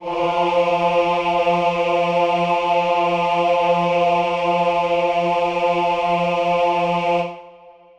Choir Piano
F3.wav